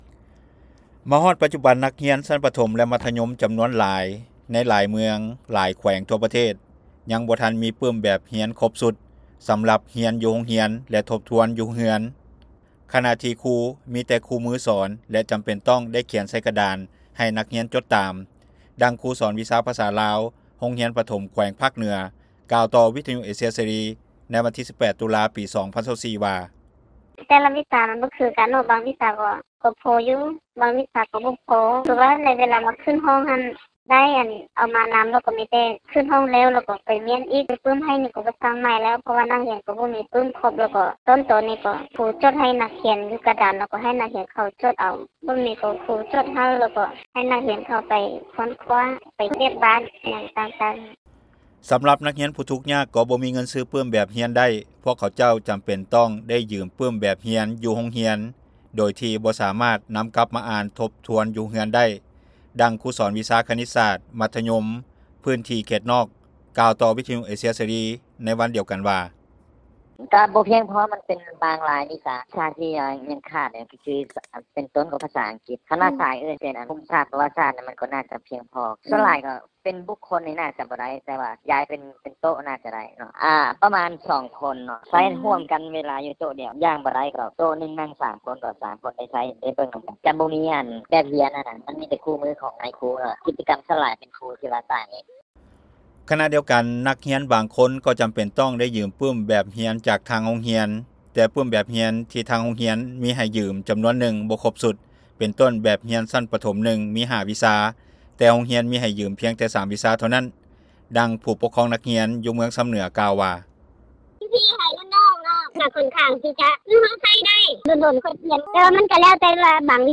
ດັ່ງຄູສອນວິຊາພາສາລາວໂຮງຮຽນປະຖົມແຂວງພາກເໜືອກ່າວຕໍ່ວິທຍຸເອເຊັຽເສຣີໃນວັນທີ 18 ຕຸລາ 2024 ວ່າ.
ດັ່ງຄູສອນວິຊາຄະນິດສາດມັດທະຍົມພື້ນທີ່ເຂດນອກກ່າວຕໍ່ວິທຍຸເອເຊັຽເສຣີໃນວັນດຽວກັນວ່າ.